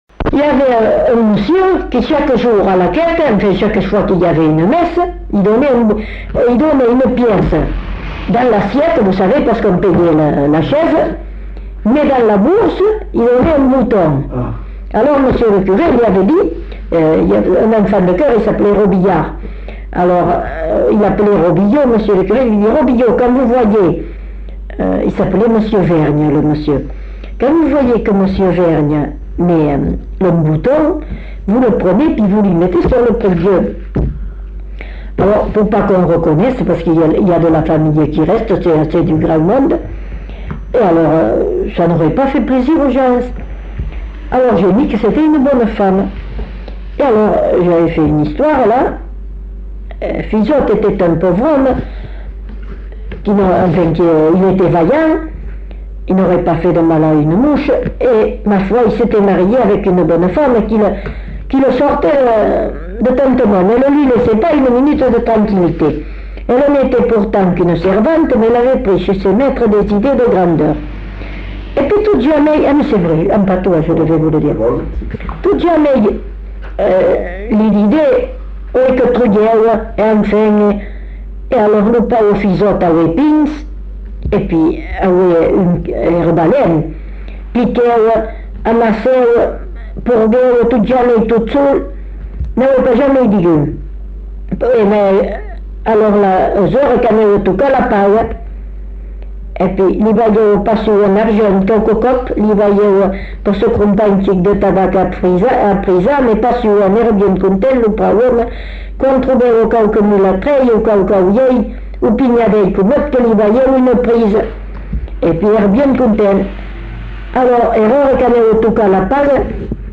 Aire culturelle : Bazadais
Lieu : Belin-Beliet
Genre : conte-légende-récit
Type de voix : voix de femme
Production du son : parlé